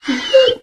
bdog_hurt_2.ogg